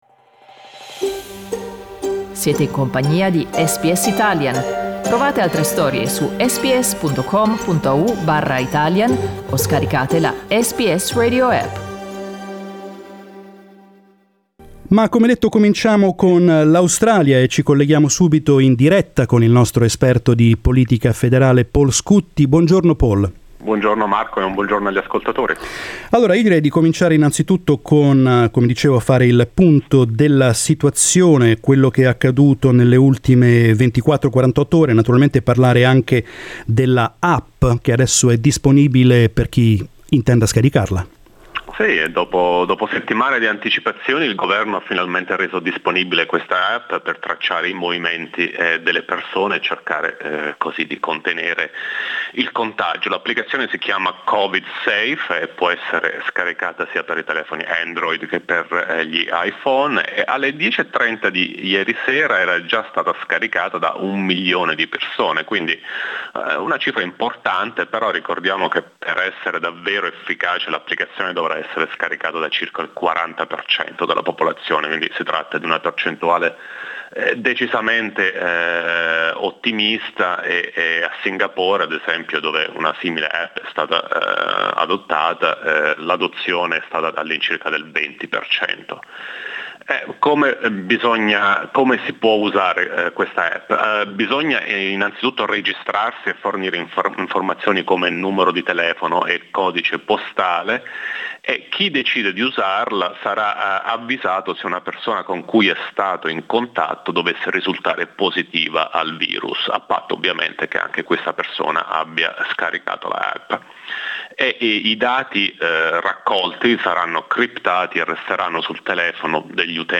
Federal politics correspondent